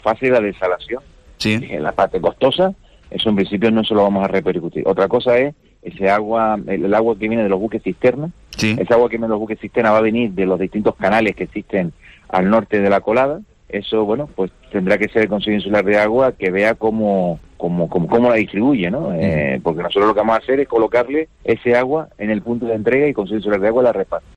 En declaraciones a La Mañana en Canarias, Valbuena ha subrayado la complejidad de poner en marcha un operativo de tal magnitud.